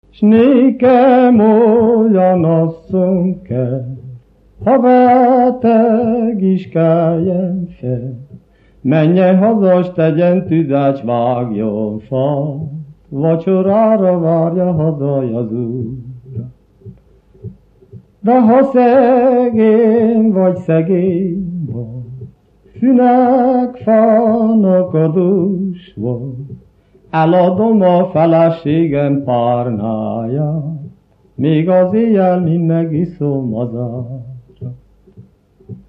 Erdély - Csík vm. - Ugrapataka (Gyimesfelsőlok)
ének
Műfaj: Lassú csárdás
Stílus: 6. Duda-kanász mulattató stílus
Szótagszám: 7.7.11.11
Kadencia: 1 (1) b3 1